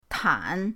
tan3.mp3